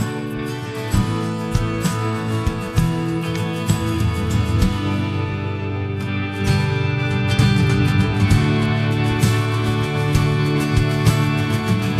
Concretamente, l’utente descrive lo stile della musica e il modello genera un estratto originale di 12 secondi.
Ad esempio, ecco la risposta al prompt “un brano folk rock degli anni ’90 con chitarra acustica, simile a Wonderwall della rock band britannica Oasis”: